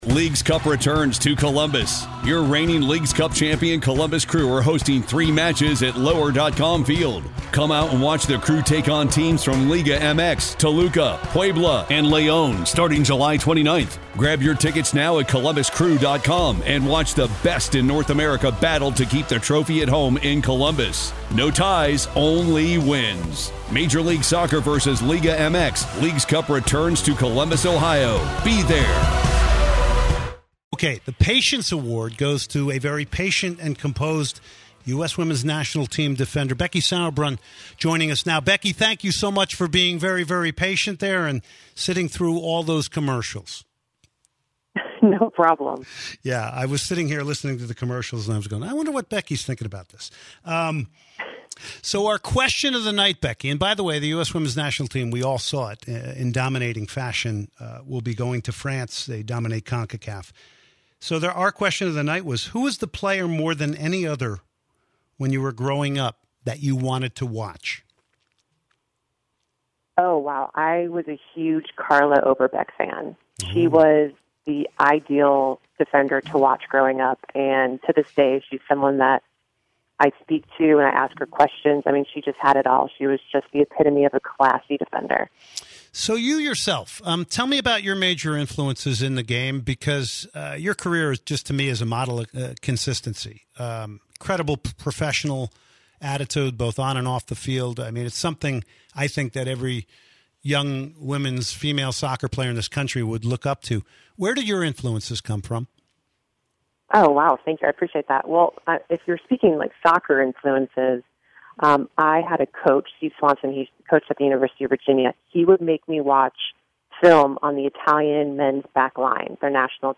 10/22/2018 Soccer Matters Interview: Becky Sauerbrunn